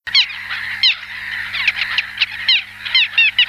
Mouette pygmée, larus minutus